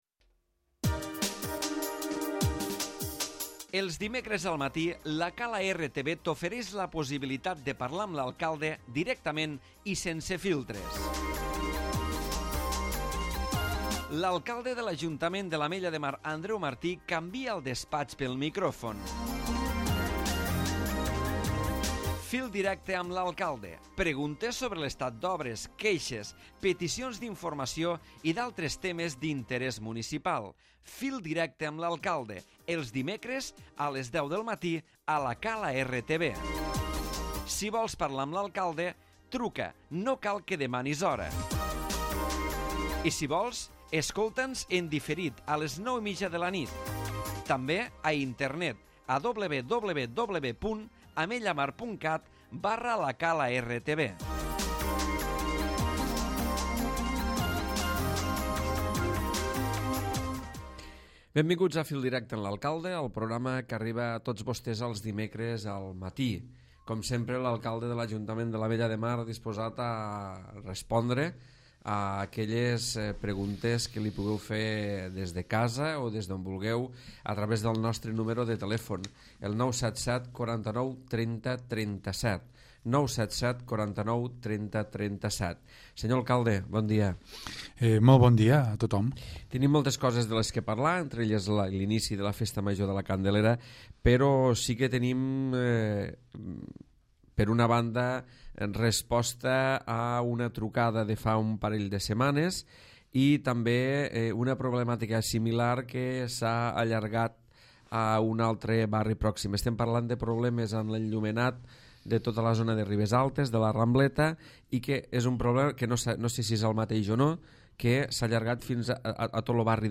L'Alcalde de l'Ajuntament de l'Ametlla de Mar, Andreu Martí, se sotmet a les trucades dels ciutadans i al repàs de l'actualitat municipal.